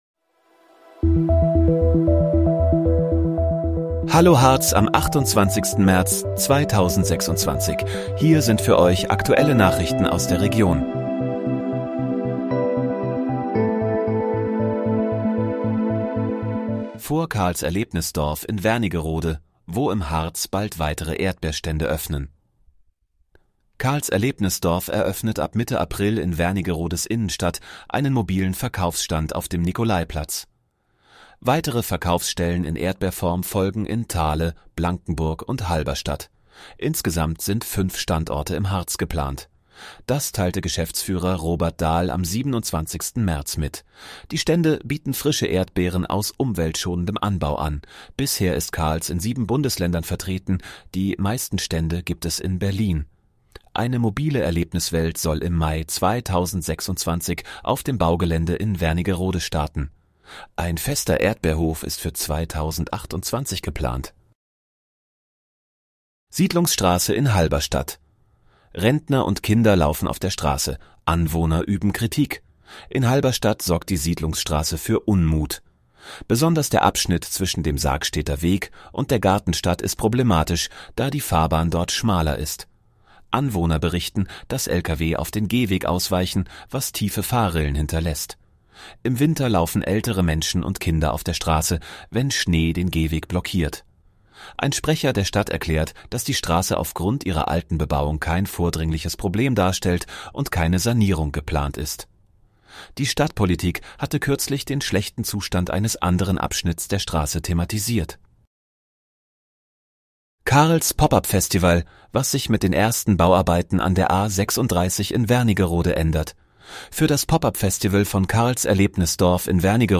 Hallo, Harz: Aktuelle Nachrichten vom 28.03.2026, erstellt mit KI-Unterstützung